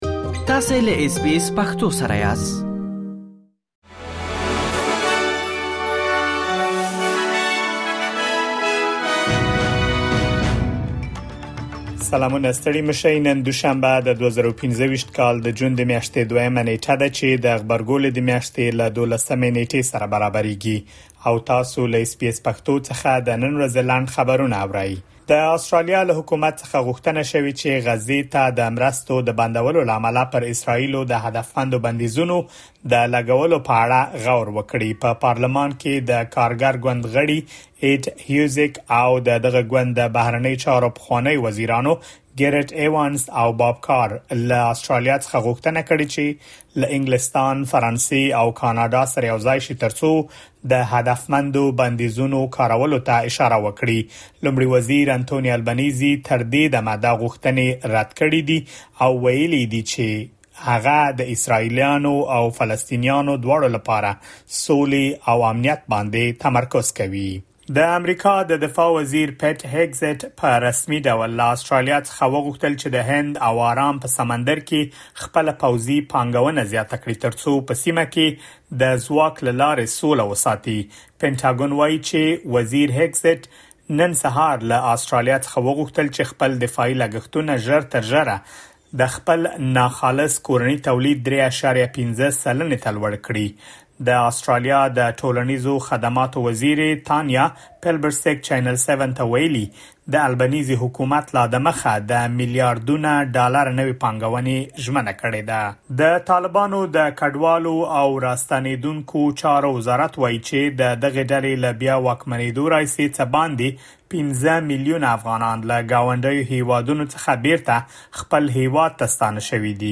د اس بي اس پښتو د نن ورځې لنډ خبرونه | ۲ جون ۲۰۲۵